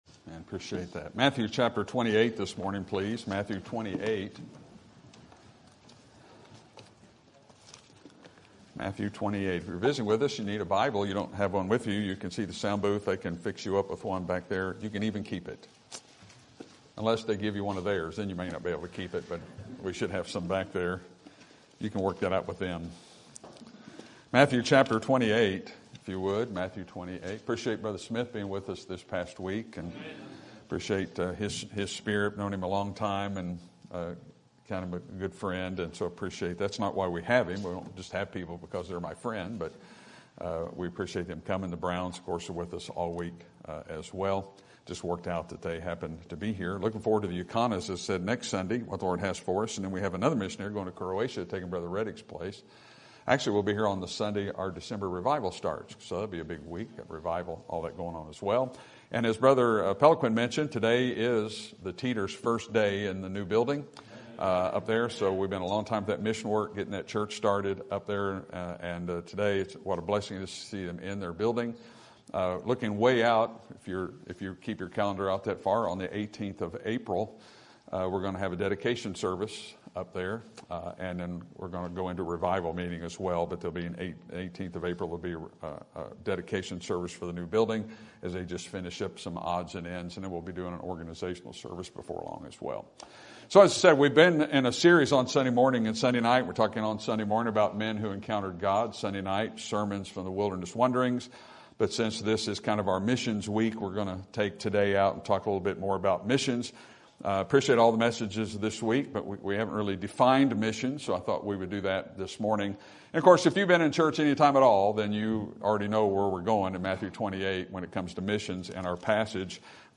Sermon Topic: General Sermon Type: Service Sermon Audio: Sermon download: Download (24.04 MB) Sermon Tags: Matthew Missions Salvation Baptism